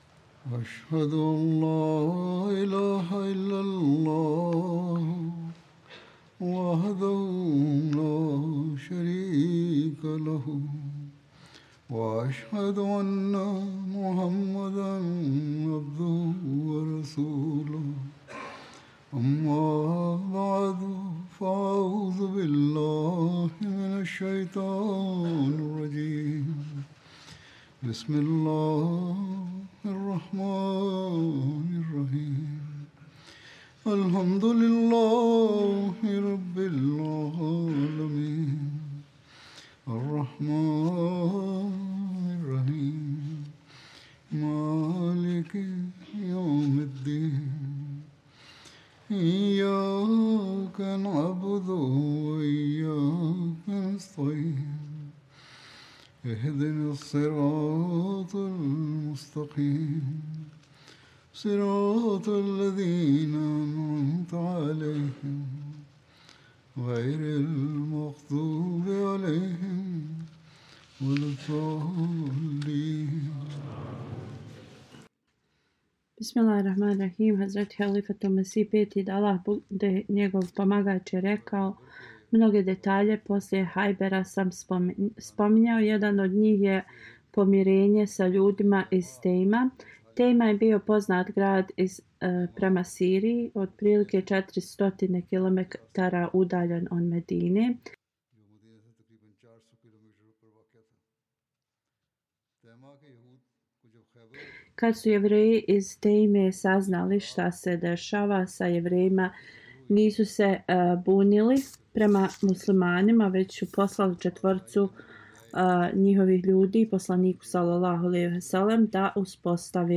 Bosnian Translation of Friday Sermon delivered by Khalifatul Masih